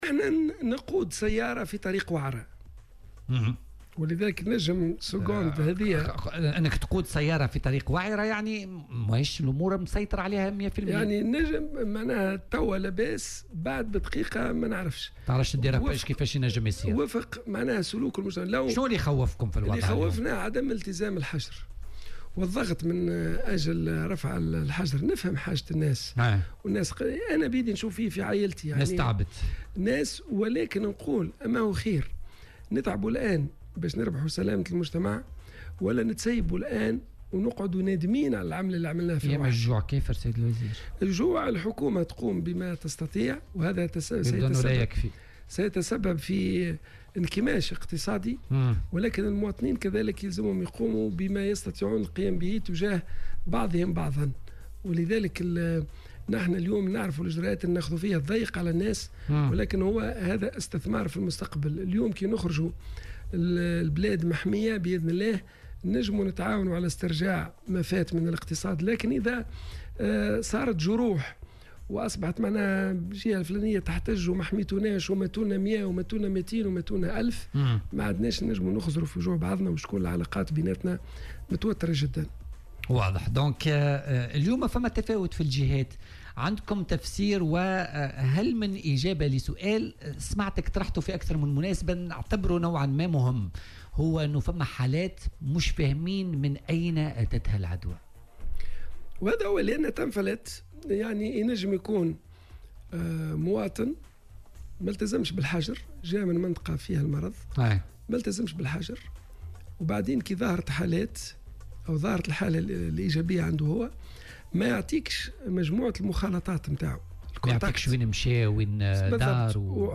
وعبّر المكي في مداخلة له اليوم في برنامج "بوليتيكا" عن تخوفه من عدم التزام بعض المواطنين بإجراءات الحجر الصحي، مشيرا إلى أن الوضع الحالي لا يسمح بتخفيف الإجراءات المتخذة.